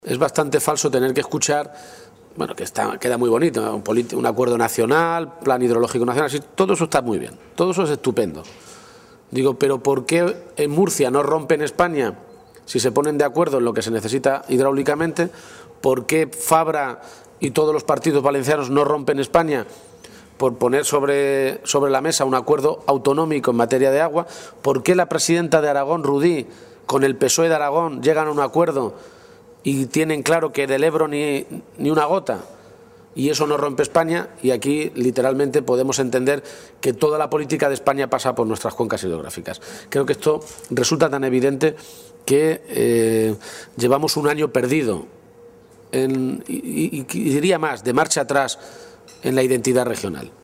García-Page hacía estas declaraciones en un desayuno informativo con representantes de los medios de comunicación en Ciudad Real, donde ha abordado diferentes asuntos de actualidad.
Cortes de audio de la rueda de prensa